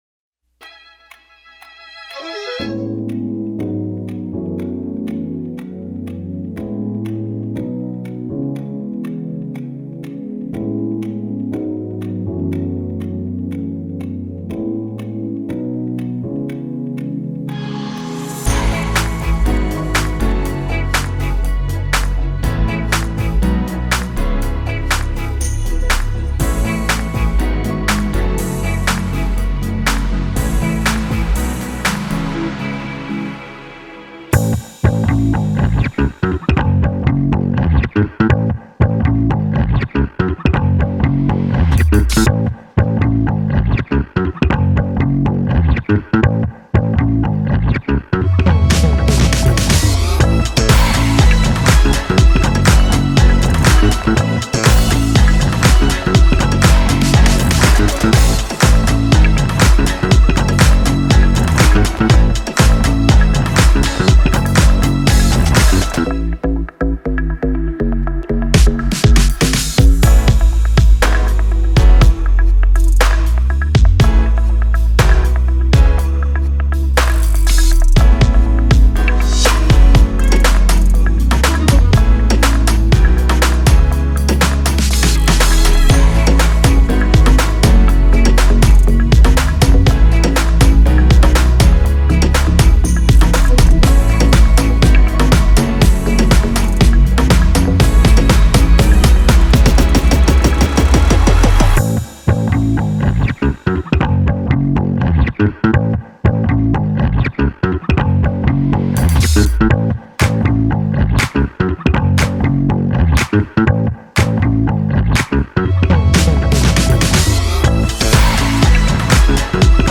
K-Pop Instrumental